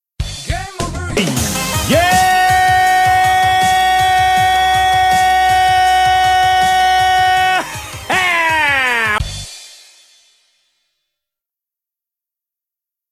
Download Game over YEEEEEEAAAAHHHH sound button
game-over-yeeeeeah.mp3